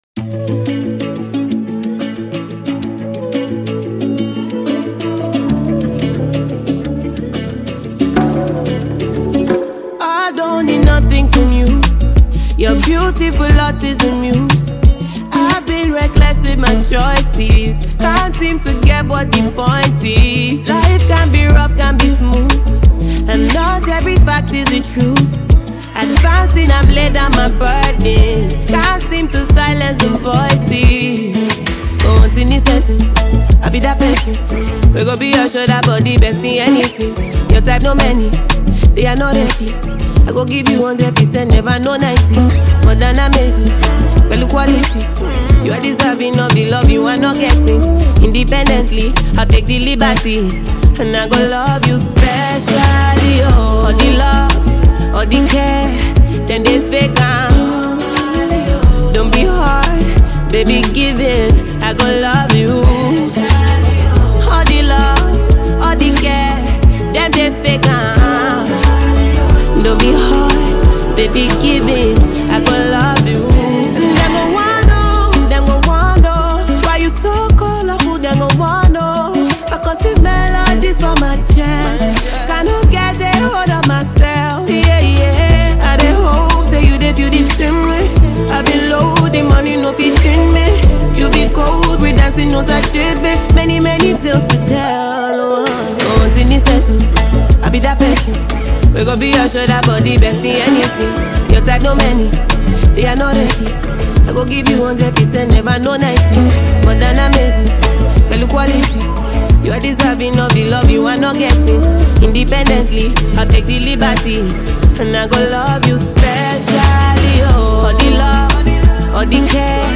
a soulful fusion of Afrobeats and R&B
expressive vocals